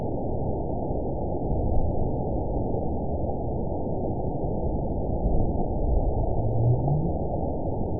event 922873 date 04/30/25 time 00:55:49 GMT (1 month, 2 weeks ago) score 9.57 location TSS-AB02 detected by nrw target species NRW annotations +NRW Spectrogram: Frequency (kHz) vs. Time (s) audio not available .wav